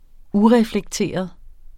Udtale [ ˈuʁεflεgˌteˀʌð ]